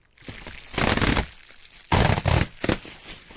沙沙的声音 " rustle.bub Rip 1
描述：用立体声的Audio Technica 853A录制的各种沙沙声
标签： 气泡 bublerap 翻录 沙沙
声道立体声